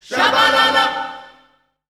Index of /90_sSampleCDs/Voices_Of_Africa/VariousPhrases&Chants